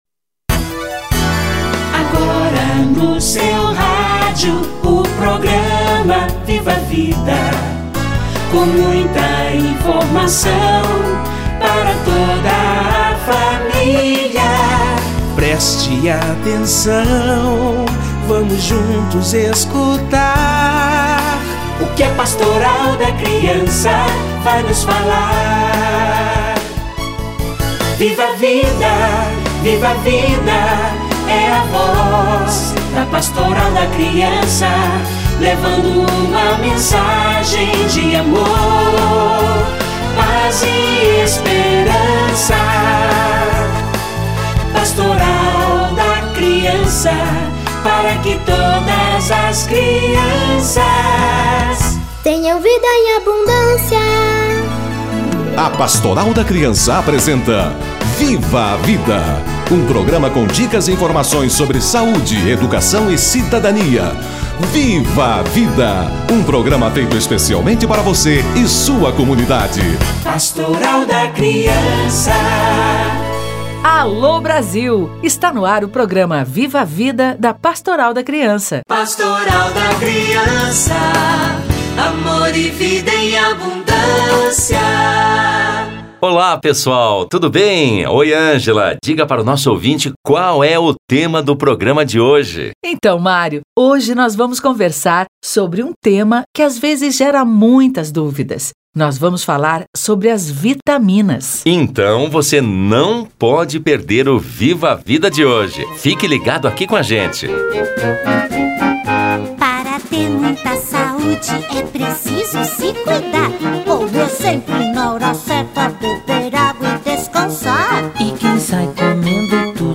Vitaminas - Entrevista